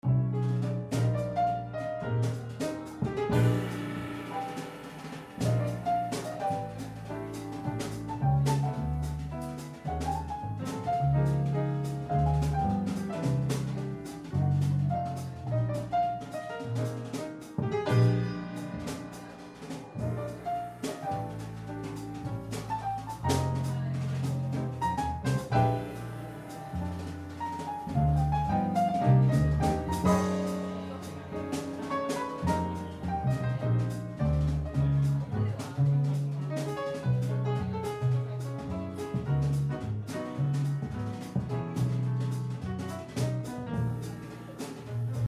Recording: Mar.〜Sep.2000 　 at　 Holly's’ & Swing　Sing